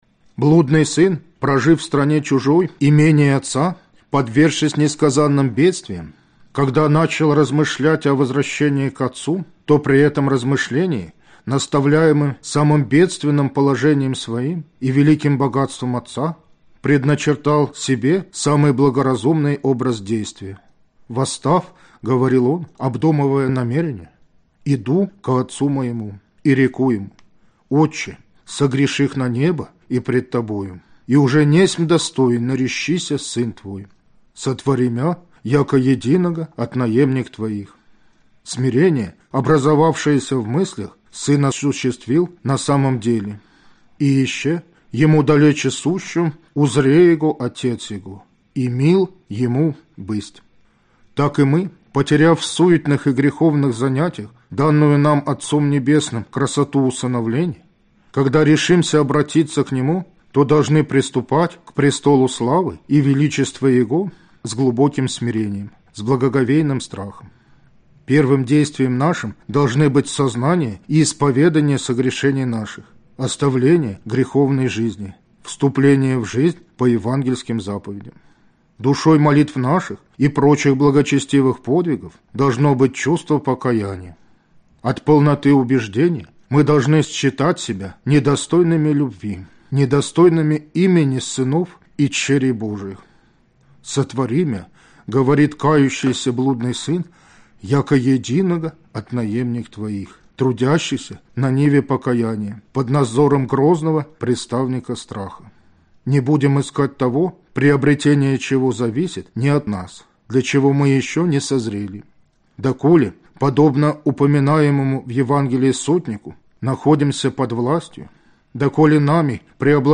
Аудиокнига Аскетические опыты 2 | Библиотека аудиокниг